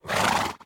mob / horse / idle2.ogg
should be correct audio levels.